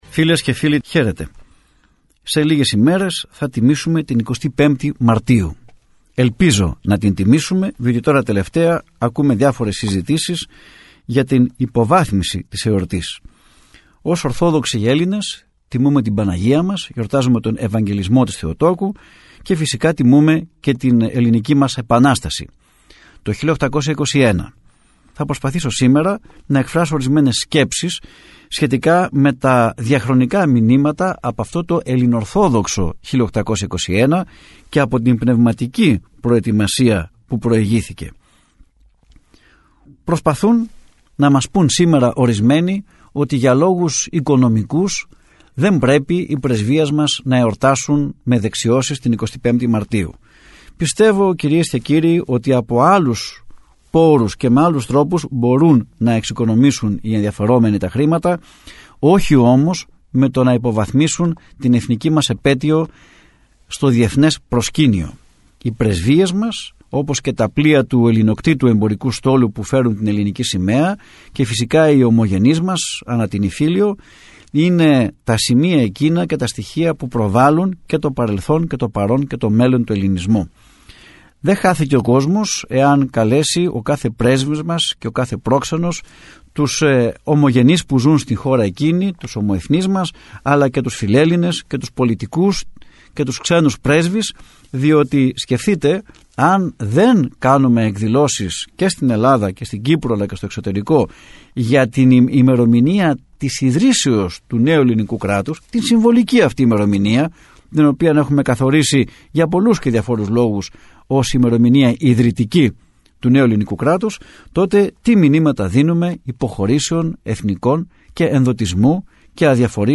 Ακούστε στην συνέχεια, ηχογραφημένα αποσπάσματα της ραδιοφωνικής εκπομπής «Ελληνορθόδοξη πορεία», που μεταδόθηκε από τον ραδιοσταθμό της Πειραϊκής Εκκλησίας.